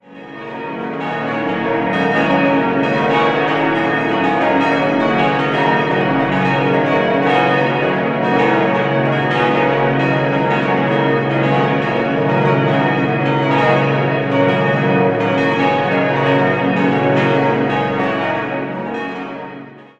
Diese wurden nach 67 Jahren durch ein neues Glockenseptett aus der Glockengießerei der Abtei Maria Laach in der Eifel gegossen. Zusammen mit der vorhandenen historischen Glocke von 1624 ergibt sich mit acht Glocken ein äußerst umfangreiches Geläut, das viele Differenzierungsmöglichkeiten zulässt.